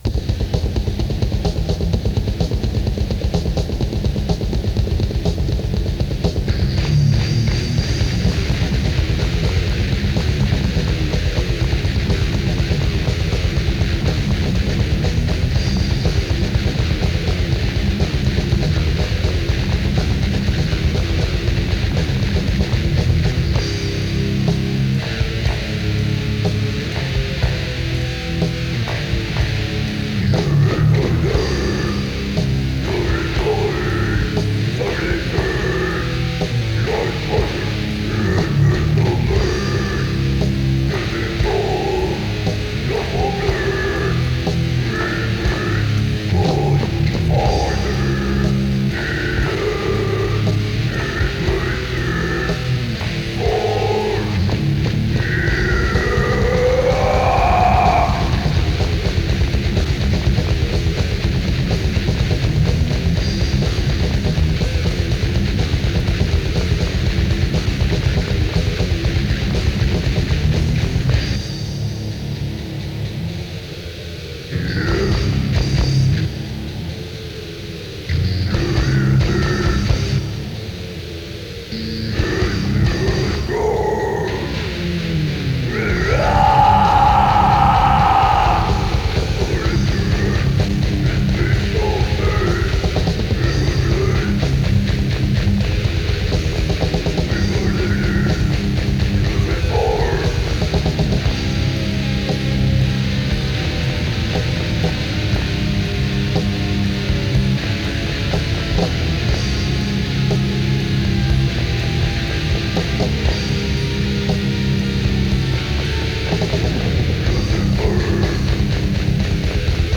Das erste und einzige Demotape der Band